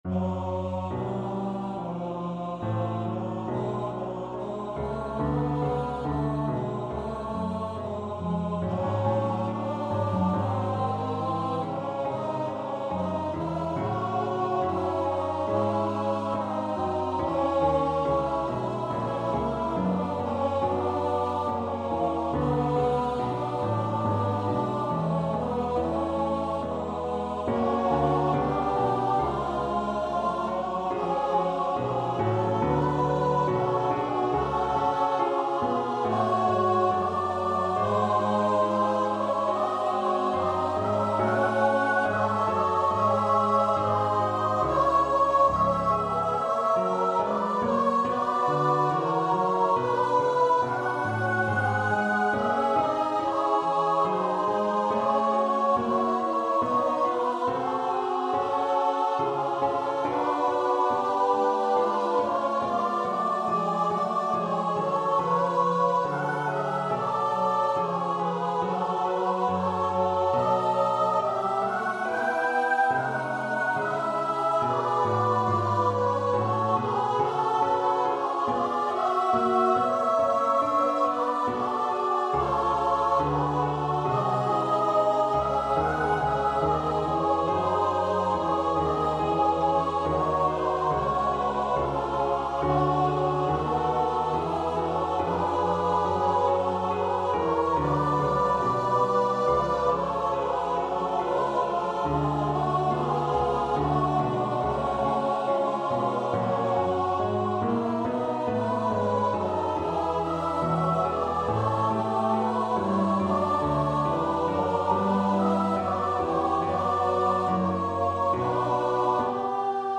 Free Sheet music for Choir (SATB)
Classical (View more Classical Choir Music)